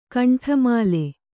♪ kaṇṭhamāle